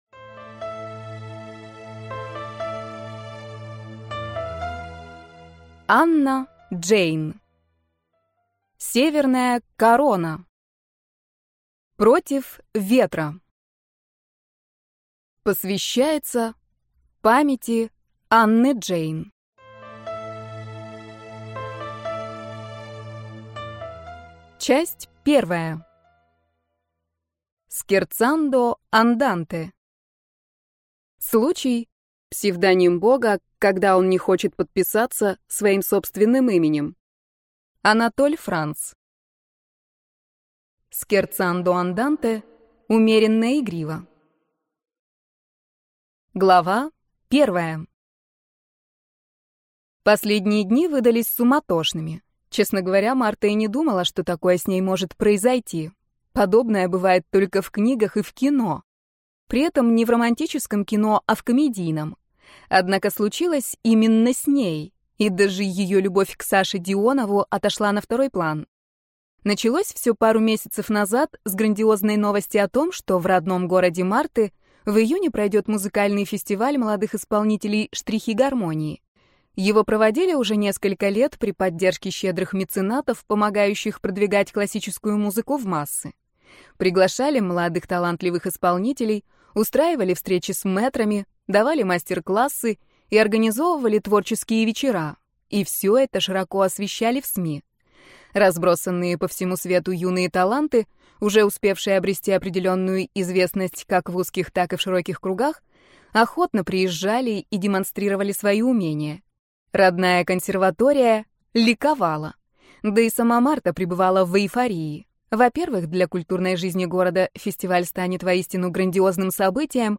Аудиокнига Северная Корона. Против ветра | Библиотека аудиокниг